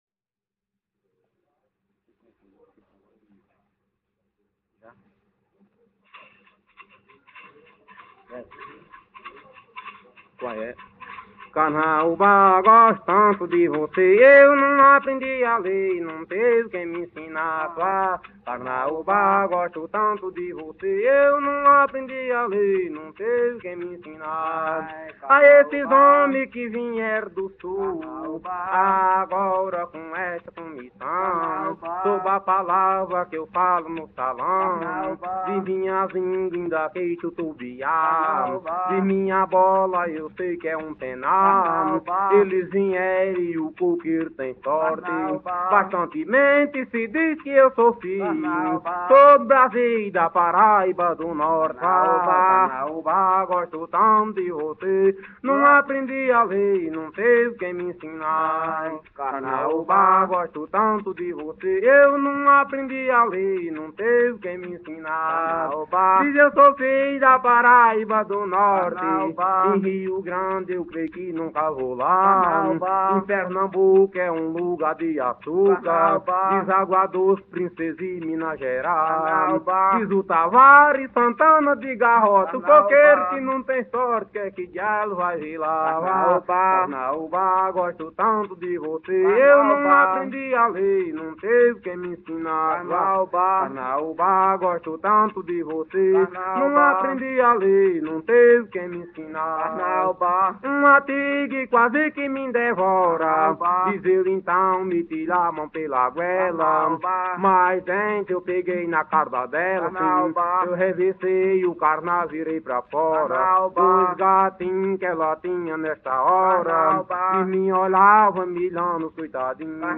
Coco martelo - ""Eu não aprendi a ler""